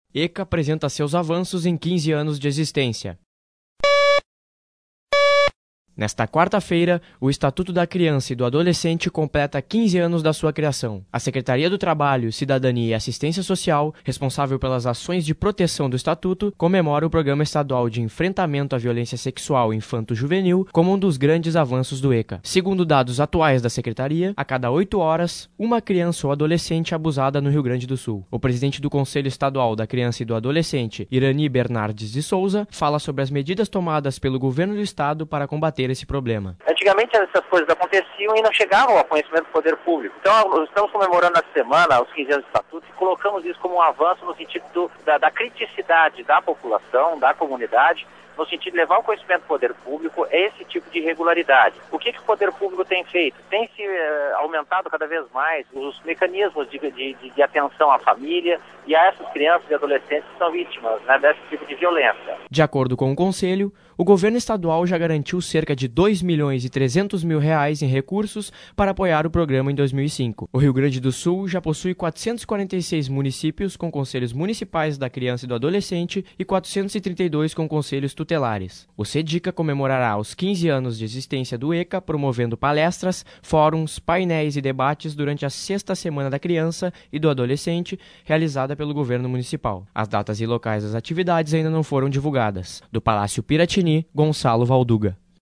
Nesta 4ª feira, o Estatuto da Criança e do Adolescente completa 15 anos da sua criação. A Secretaria do Trabalho comemora o Programa Estadual de Enfrentamento à Violência Sexual Infanto-juvenil com um os grandes avanços do ECA. Sonora: presidente do C